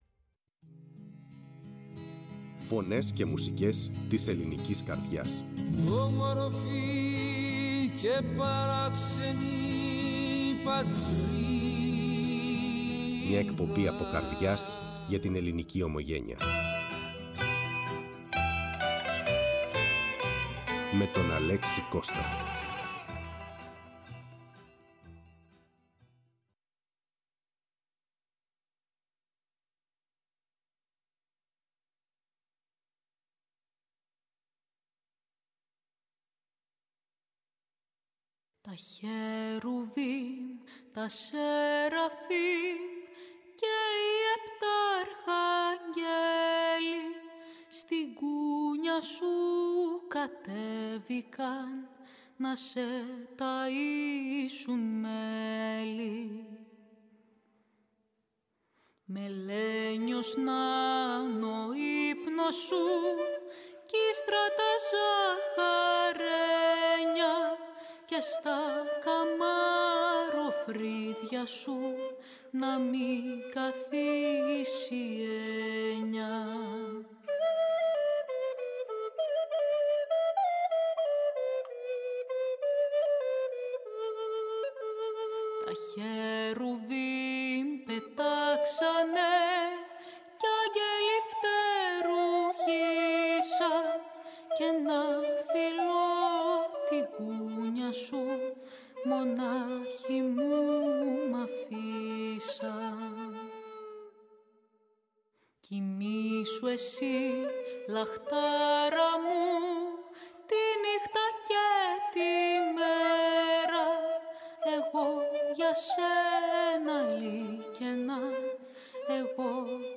Η ΦΩΝΗ ΤΗΣ ΕΛΛΑΔΑΣ Φωνες και Μουσικες ΜΟΥΣΙΚΗ Μουσική ΣΥΝΕΝΤΕΥΞΕΙΣ Συνεντεύξεις